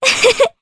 Artemia-Vox-Laugh_jp.wav